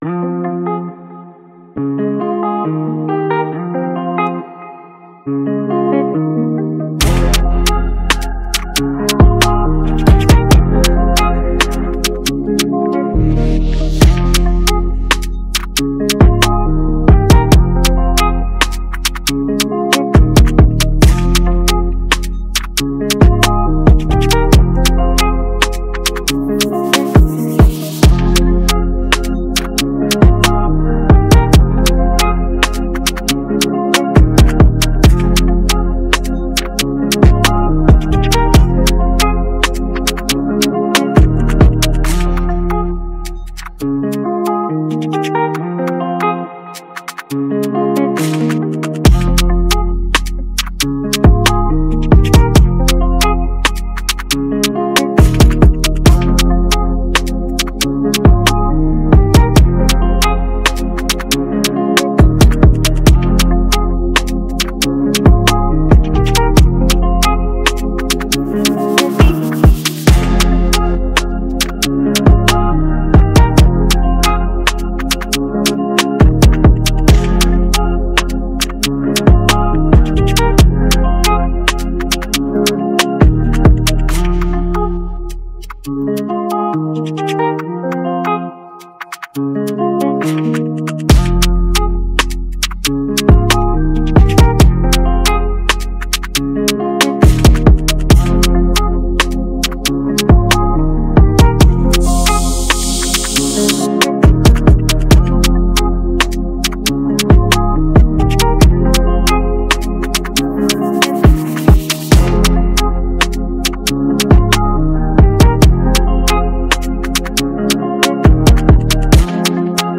Drill beats Hip hop hiphop trap beats
Tagged afrobeats